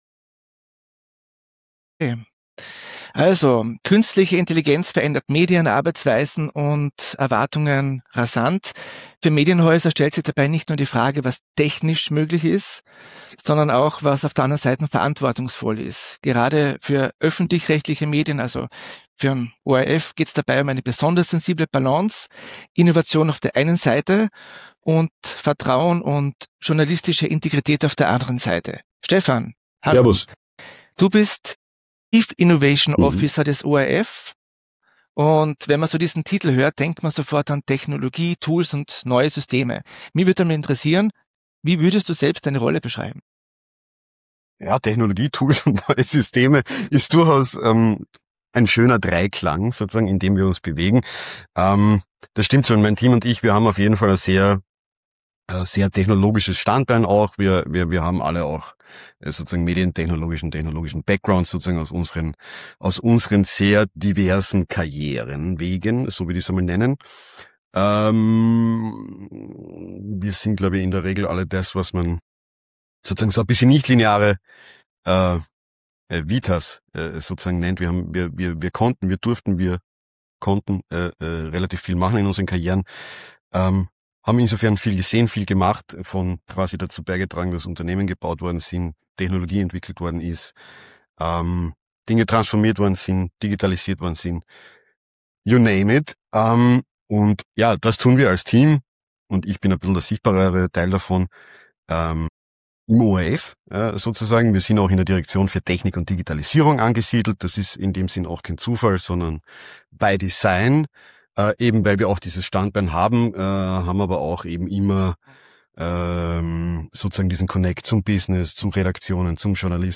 Im Gespräch geht es um die Balance zwischen Innovation und Verantwortung, um konkrete KI-Use-Cases im ORF, um Lernkultur, Governance und Haltung. Und darum, warum KI im Journalismus vor allem unterstützen soll, nicht ersetzen.